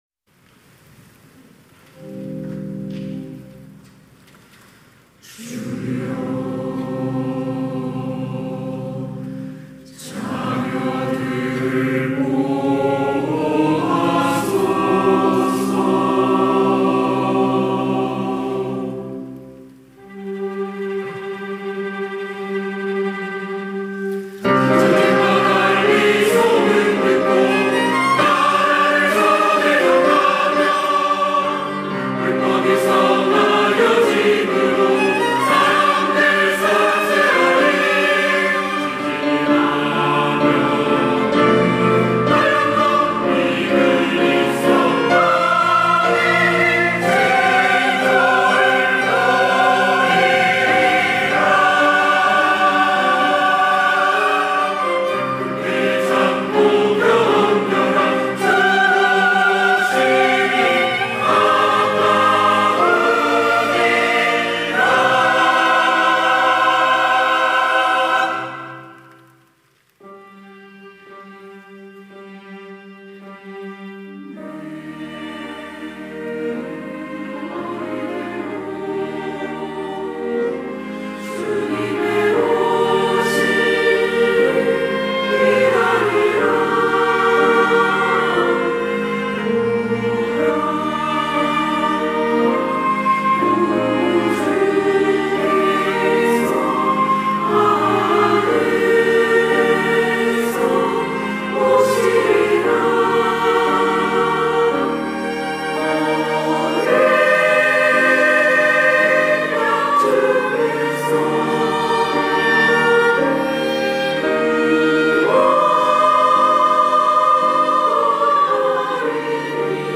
호산나(주일3부) - 보라 주 오시리라
찬양대